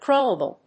crawlable.mp3